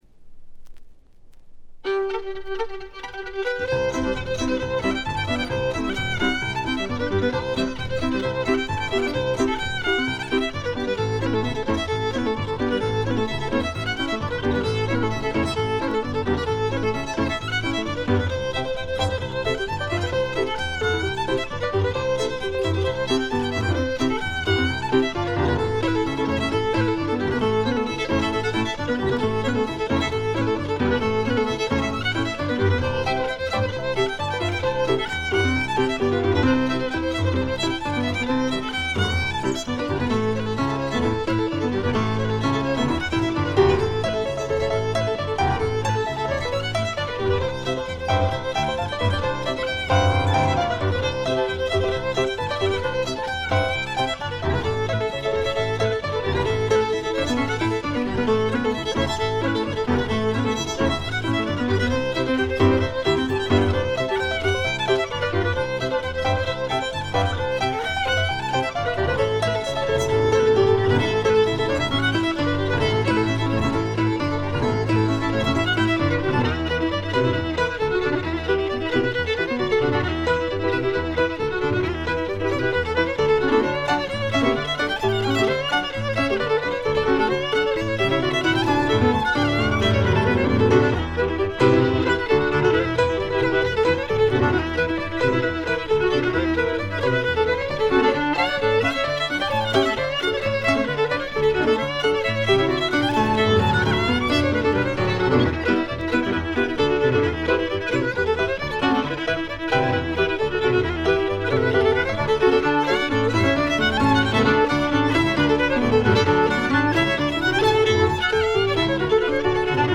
ところどころでバックグラウンドノイズ、チリプチ。散発的なプツ音少々。
試聴曲は現品からの取り込み音源です。
Fiddle
Piano